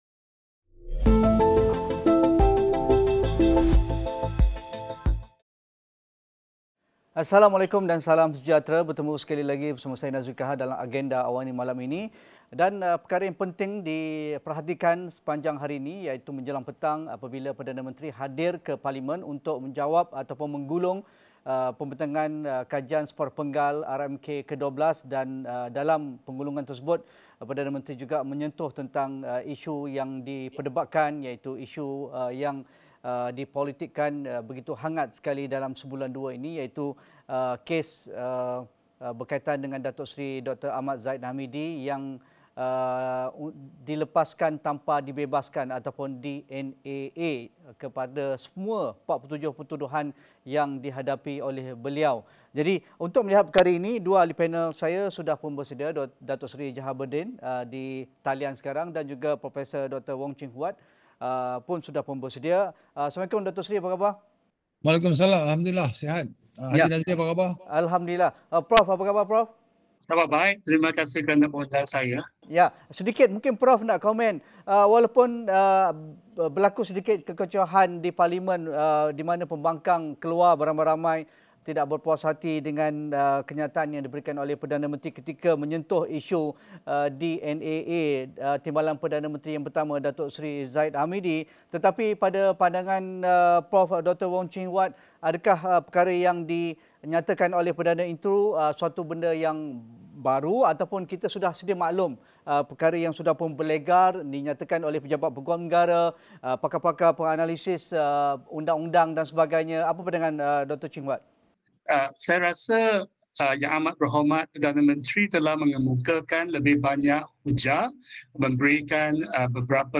Headliner Embed Embed code See more options Share Facebook X Subscribe Penjelasan Perdana Menteri, Datuk Seri Anwar Ibrahim berhubung keputusan melepaskan tanpa membebaskan (DNAA) mengundang ‘kekecohan’ sehingga Ahli Parlimen Pembangkang keluar dari Dewan Rakyat. Namun, adakah penjelasan mengenai bidang kuasa dan budi bicara Peguam Negara dapat difahami dengan baik? Diskusi 8.30 malam